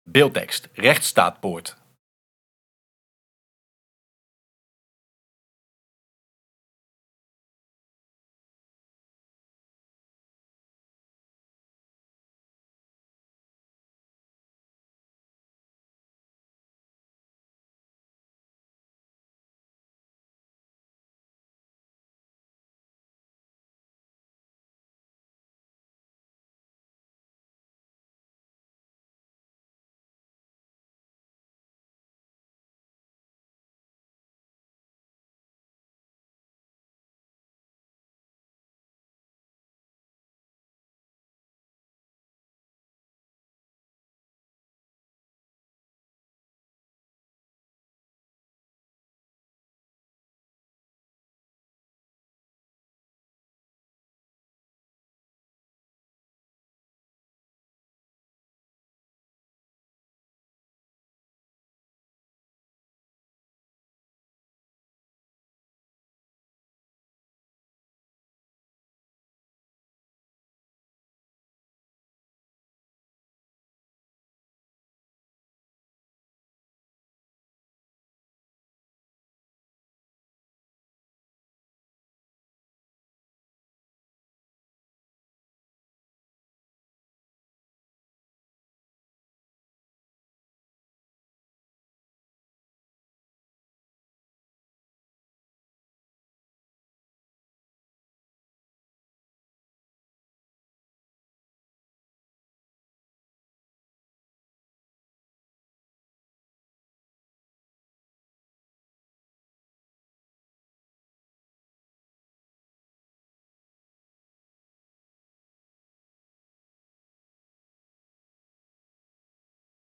Op 21 juni 2021 vond Rechtsstaatpoort plaats in Nieuwspoort. Hier gingen bestuurders van OM, politie, Raad voor de Rechtspraak, Reclassering Nederland en de gemeente in debat met Tweede Kamerleden. Centraal stond de vraag: wat is de Rechtsstaat ons waard?